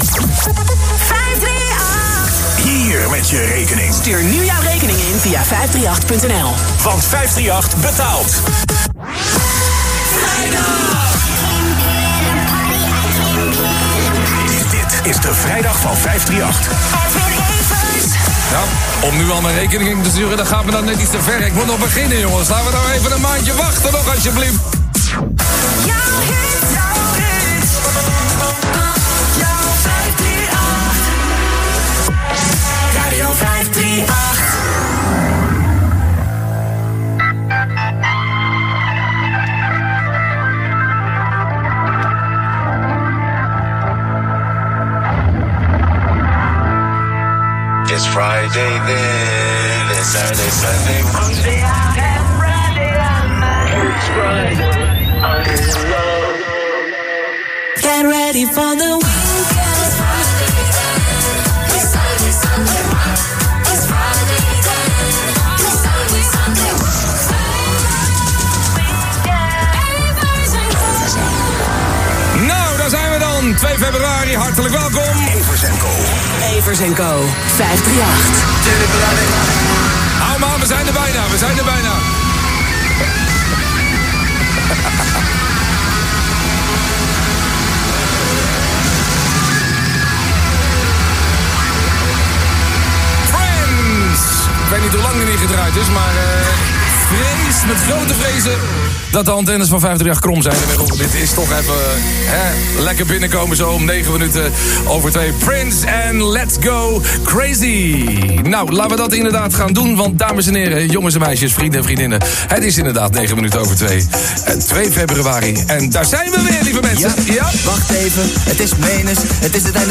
Nou daar zijn we dan,” met deze woorden opende Edwin Evers zijn eerste vrijdagmiddag show op Radio 538.
Met ‘Let’s Go Crazy’ van Prince als eerste plaat gaf Evers dan ook gelijk de toon aan: heerlijk het weekend van start gaan.